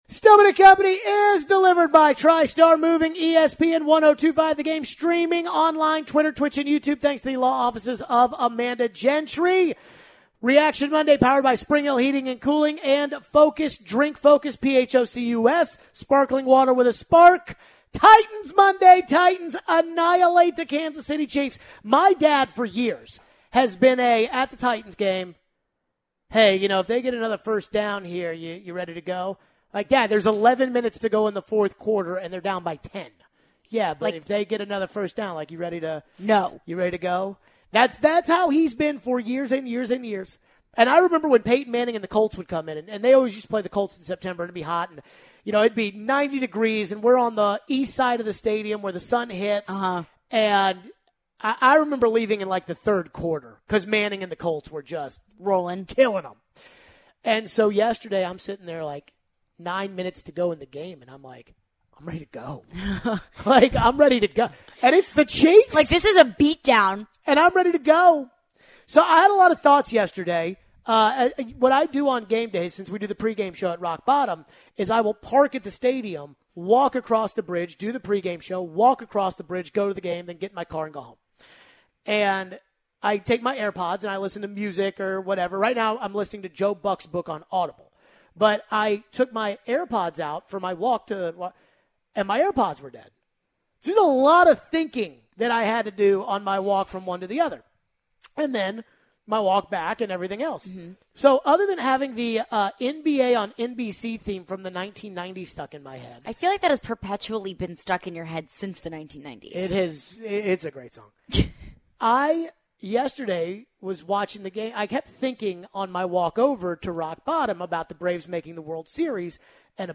We go back to your phones.